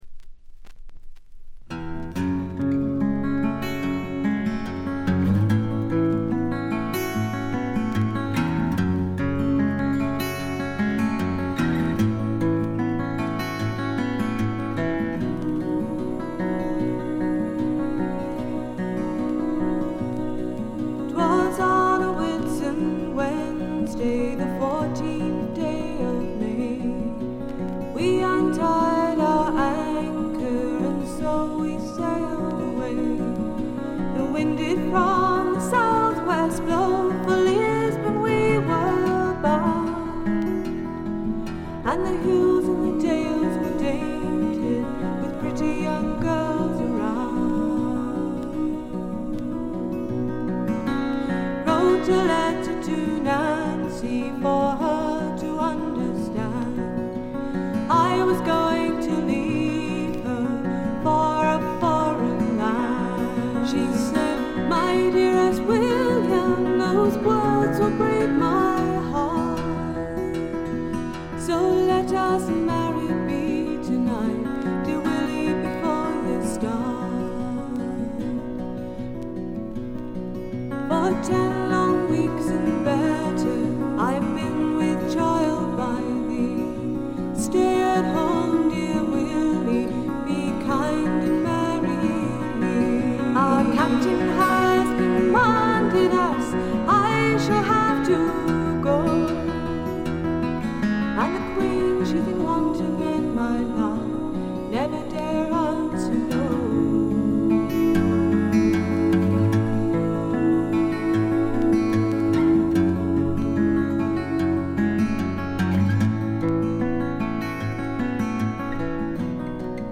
ごくわずかなノイズ感のみ。
基本はギター弾き語りで曲によってベースやシンセが入るといったシンプルな構成で、ゆったりと落ち着いた内容です。
試聴曲は現品からの取り込み音源です。
Vocals, Guitar